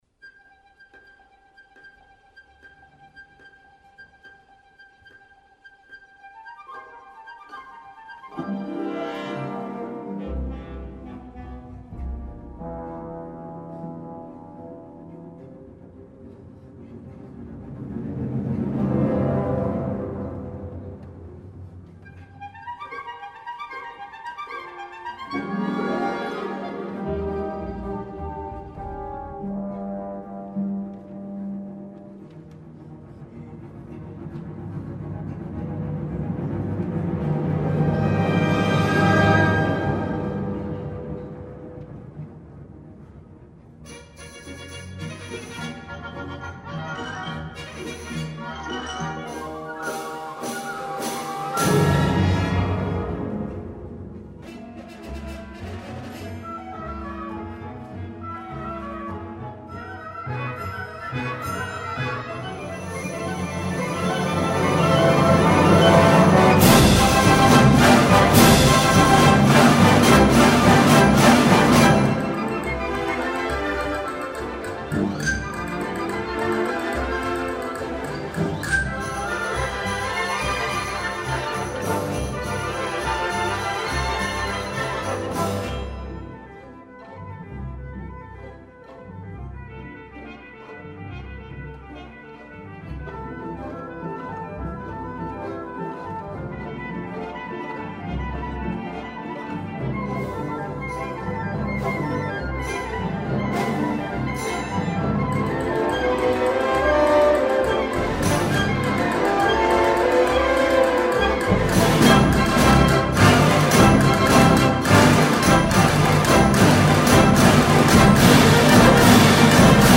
Eigenproduktionen des Symphonieorchesters der Stadt Münster